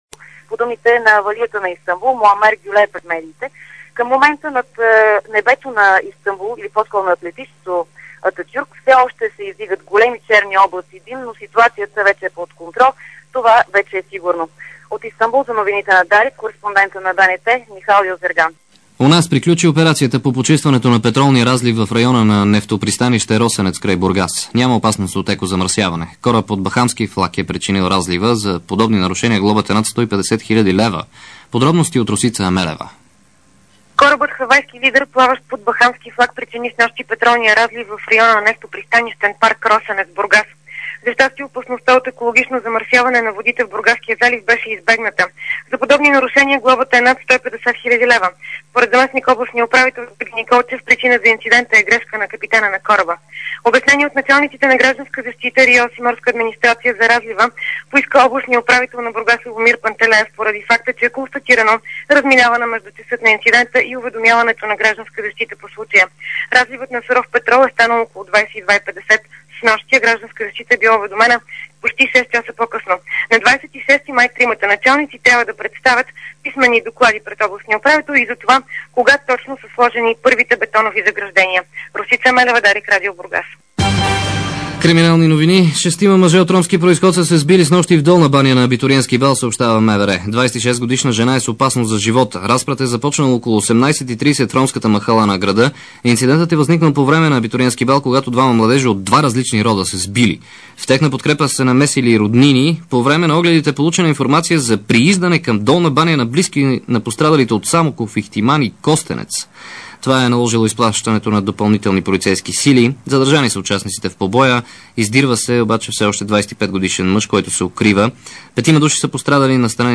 DarikNews audio: Обзорна информационна емисия – 24.05.2006